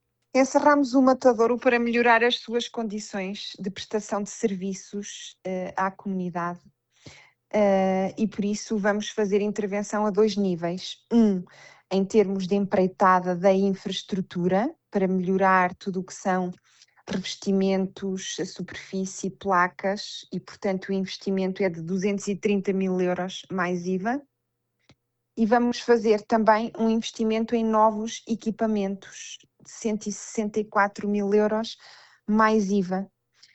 A presidente da Câmara Municipal de Bragança, Isabel Ferreira, explica que a decisão resulta da necessidade de modernizar o espaço e reforçar as condições de segurança alimentar: